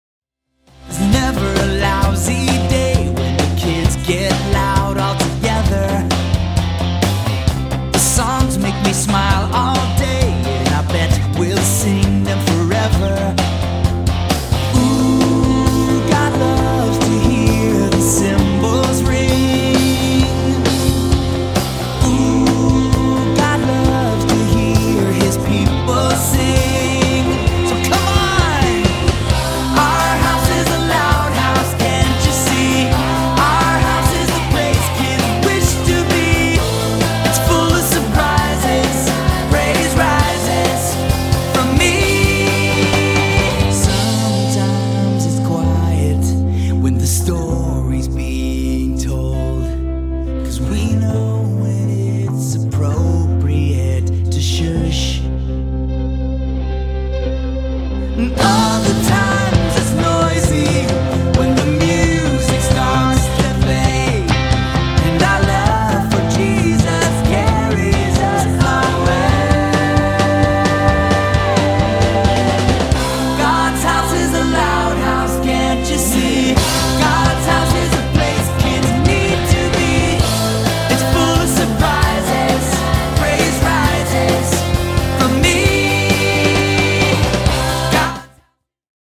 energetic praise and worship music